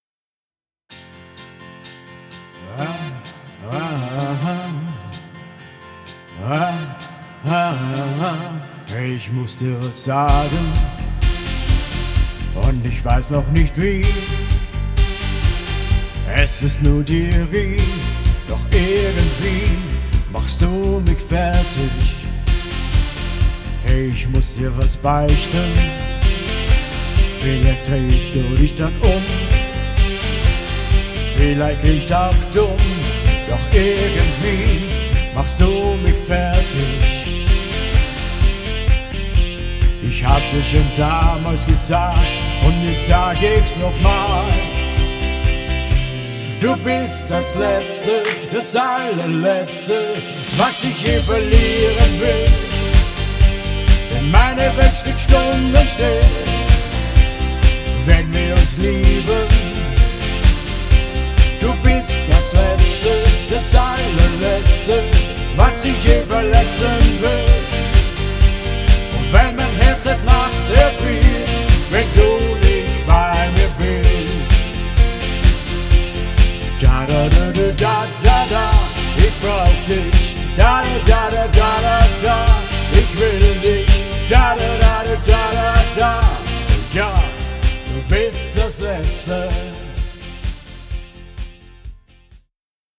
Schlager & Apres Ski & Party